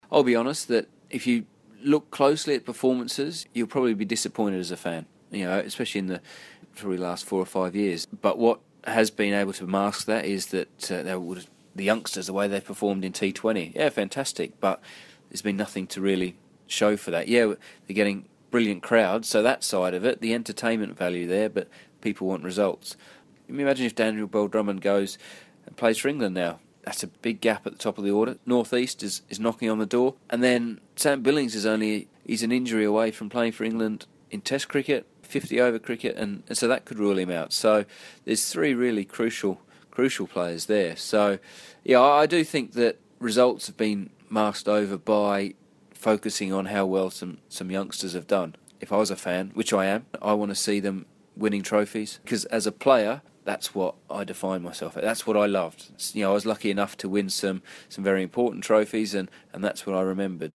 Ex-Kent wicketkeeper Geraint Jones tells BBC Radio Kent that results over the past few seasons have not been good enough.